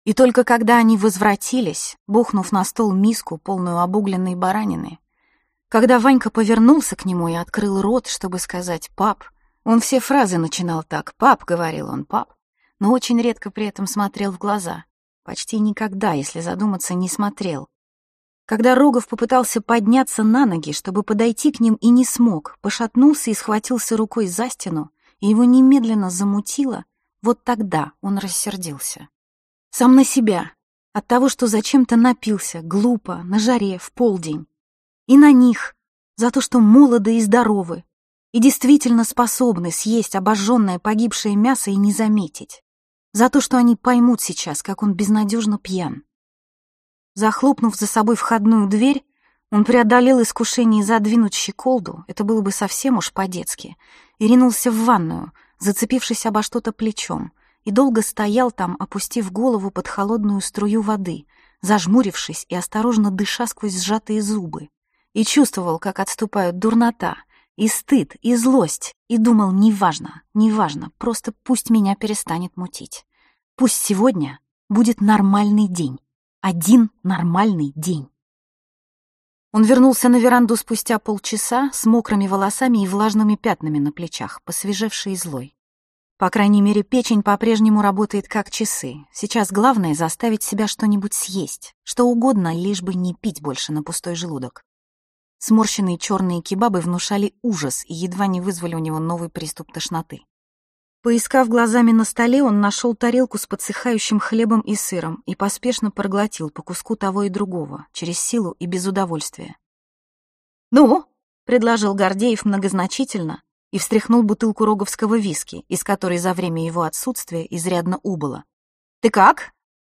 Аудиокнига Один нормальный день | Библиотека аудиокниг
Aудиокнига Один нормальный день Автор Яна Вагнер Читает аудиокнигу Яна Вагнер.